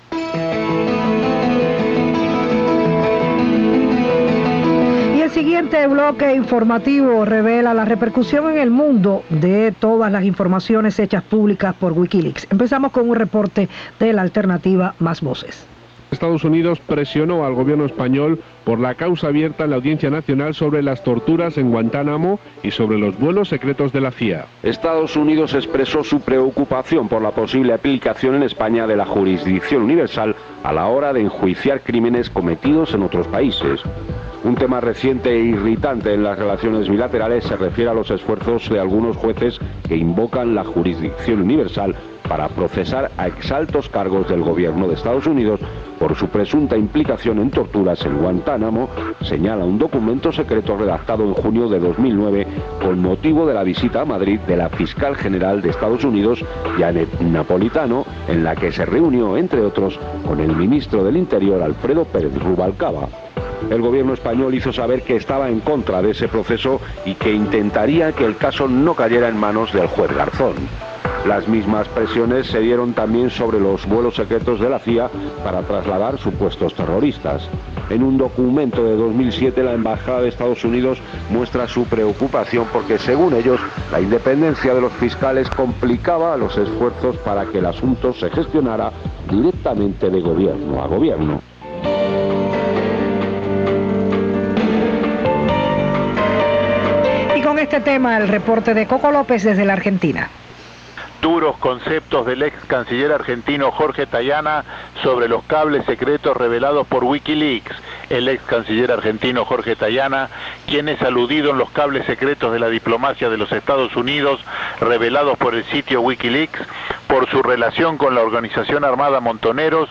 Radio Habana Cuba la radiodifusora de onda corta de Cuba presenta diversos reportes de las reacciones en algunos paises de latinoamerica con respecto a la información publicada en Wikileaks.